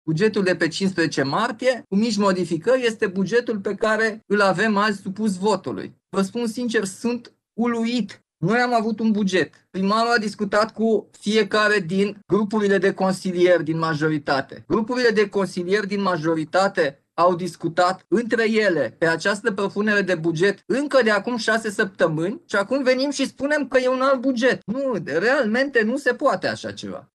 În replică, primarul general Nicuşor Dan spune că proiectul a fost discutat de ceva timp cu toţi consilierii: